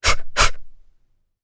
Requina-Vox_Attack2_jp.wav